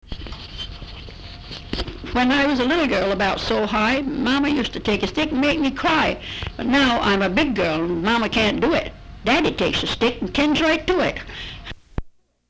Folk songs, English--Vermont (LCSH)
sound tape reel (analog)
Location Guilford, Vermont